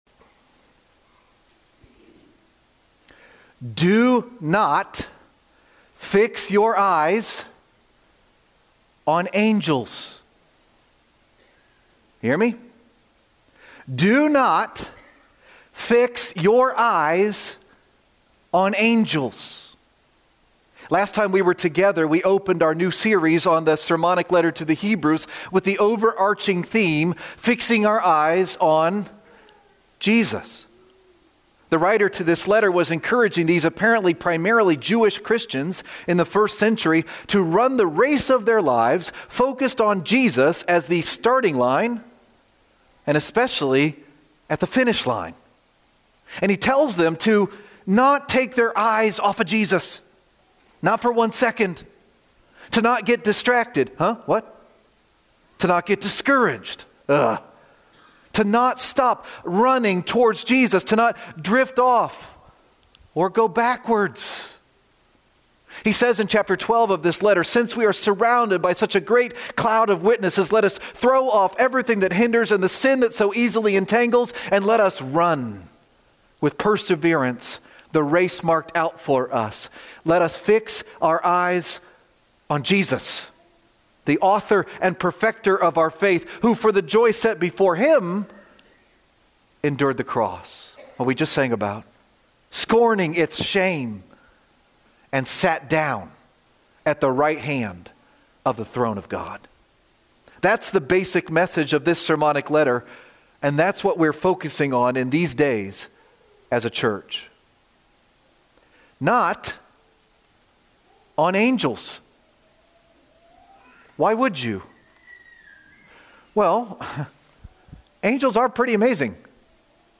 Superior To The Angels :: February 1, 2026 - Lanse Free Church :: Lanse, PA